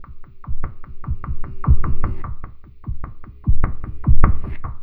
Abstract Rhythm 45.wav